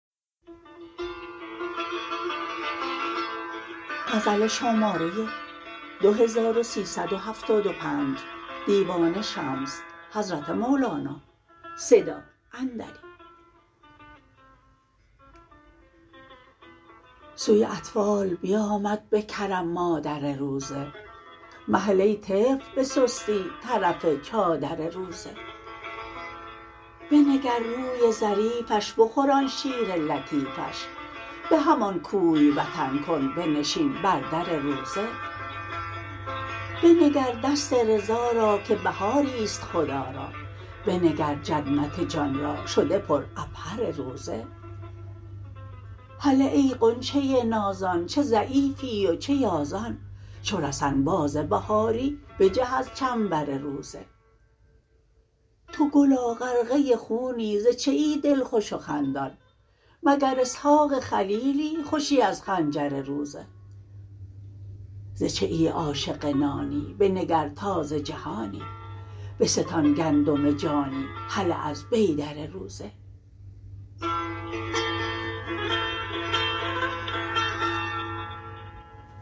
خوانش شعر